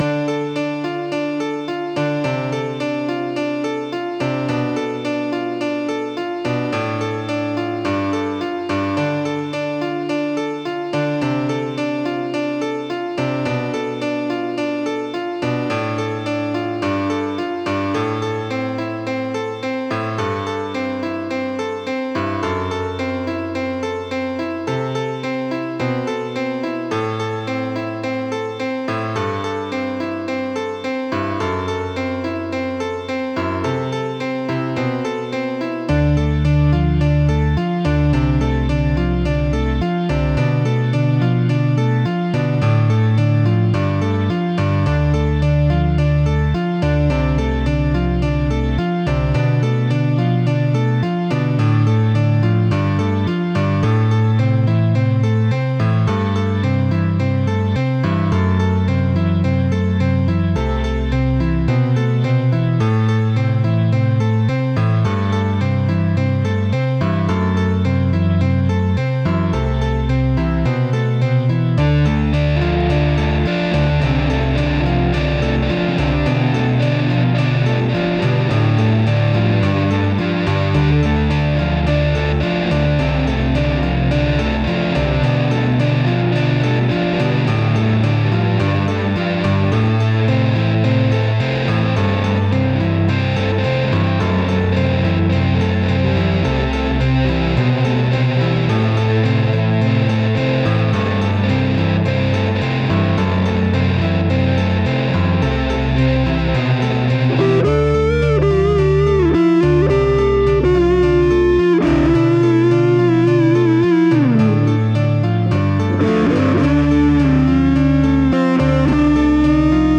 • Жанр: Фолк
Инструментальная коммпозиция